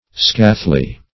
Scathly \Scath"ly\, a. Injurious; scathful.